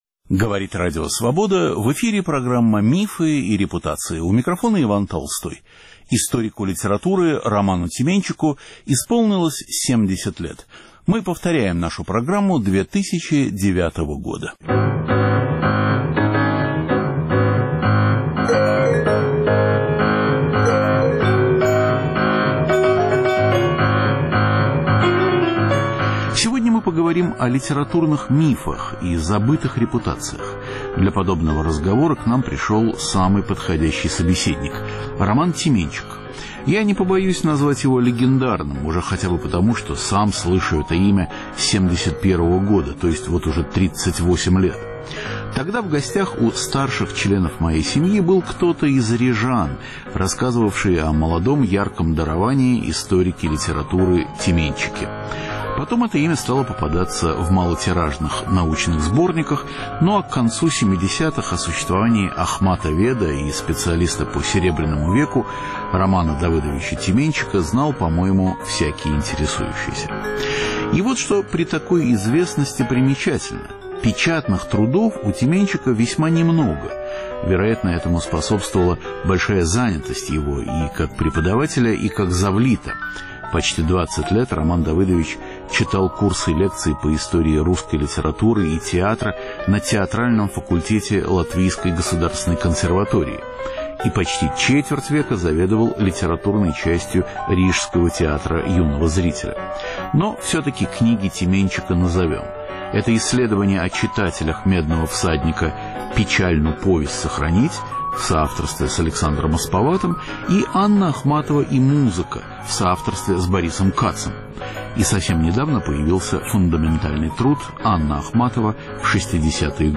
Одному из самых знаменитых литературоведов нашего времени – Роману Тименчику – исполнилось 70 лет. Беседа в студии об изучении Серебряного века.